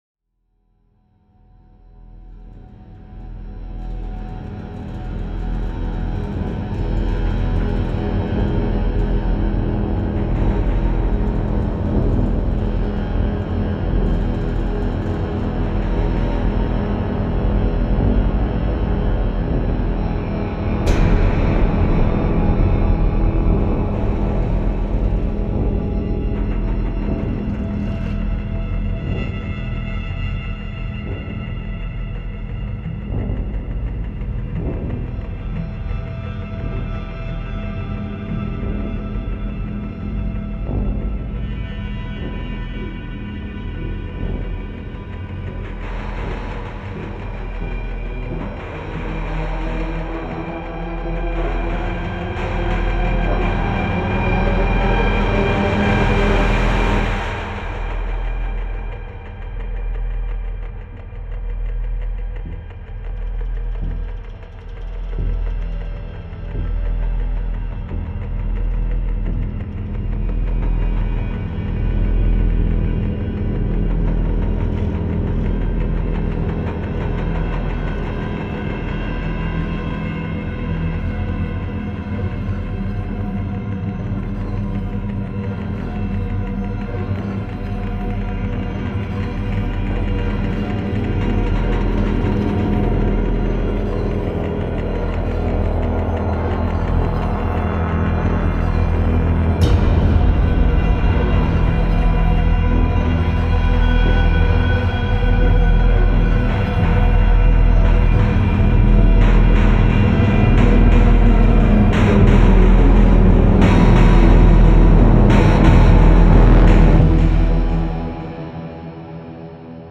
Thriller